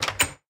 door_open.mp3